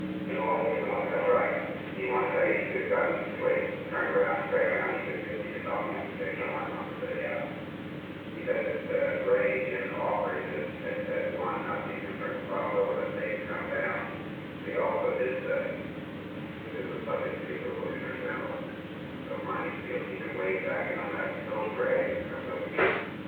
Secret White House Tapes
Location: Executive Office Building
The President listened to a portion of a recording of a meeting with John W. Dean, III.
The President stopped the recording at an unknown time before 12:51 pm.